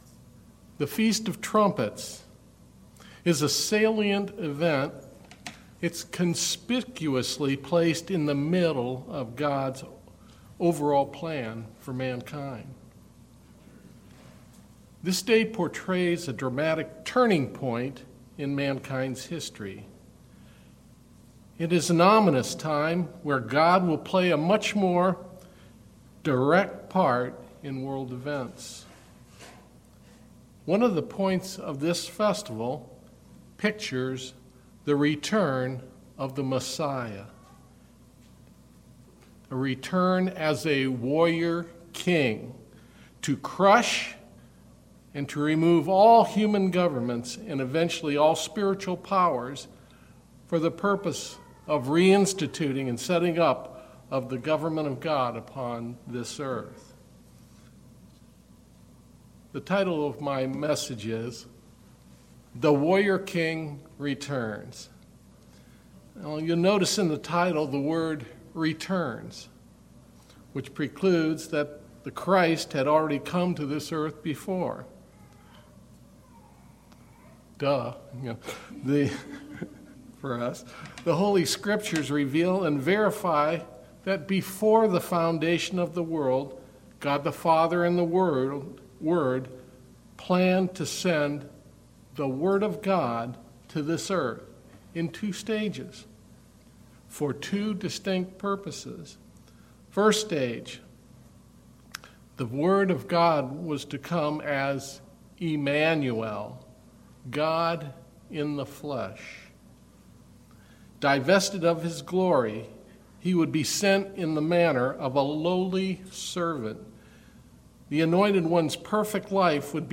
Sermons
Given in Mansfield, OH